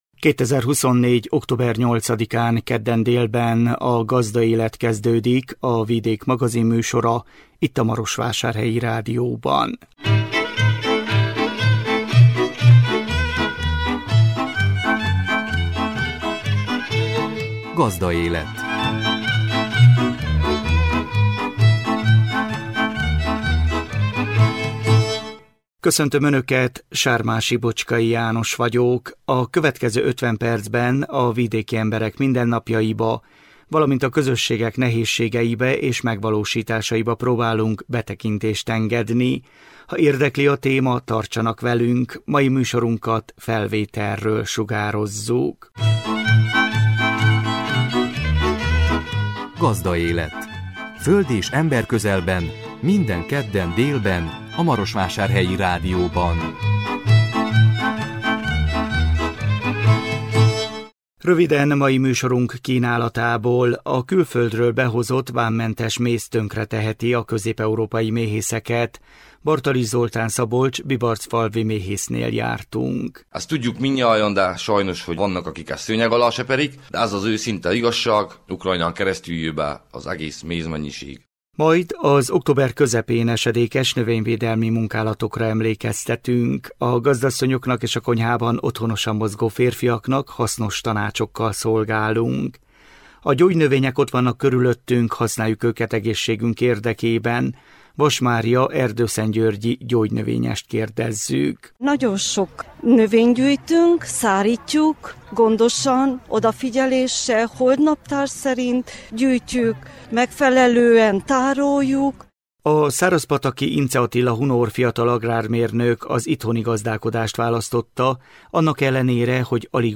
Szombaton délután ünnepélyes keretek között került sor a havadtői szépkorúak köszöntésére. Mikrofonunkkal mi is ott jártunk.